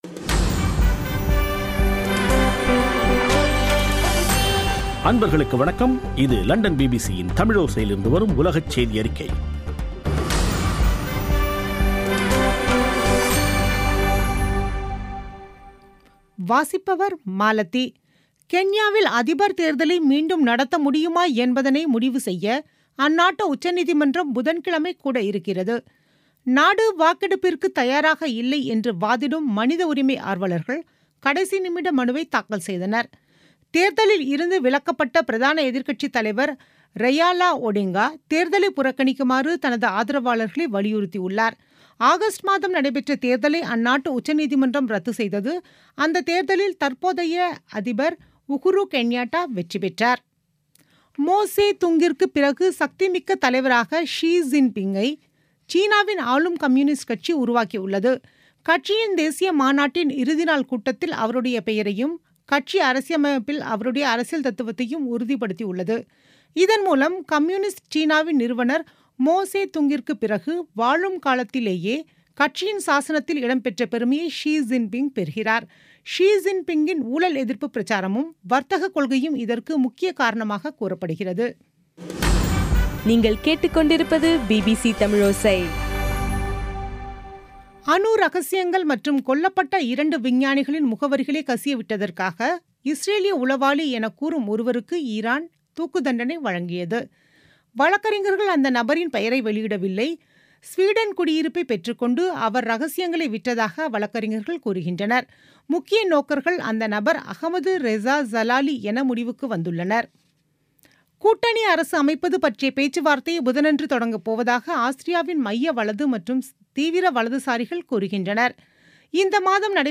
பிபிசி தமிழோசை செய்தியறிக்கை (24.10.2017)